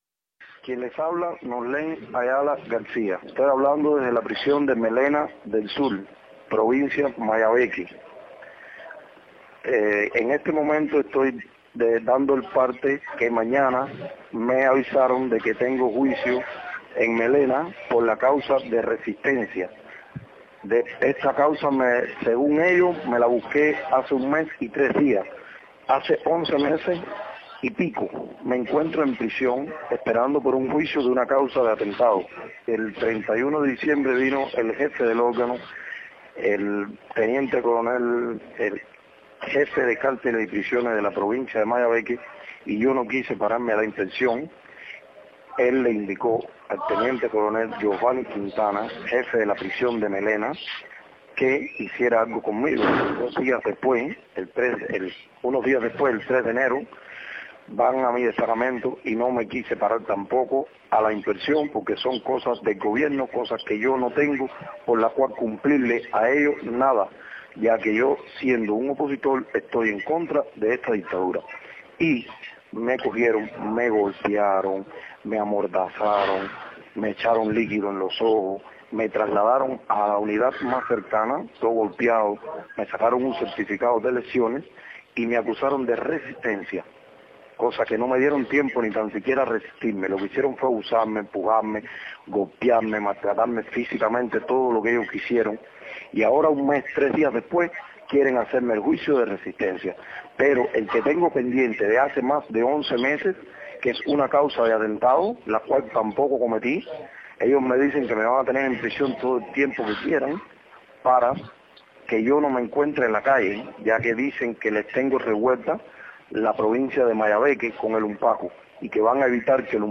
llamada desde prisión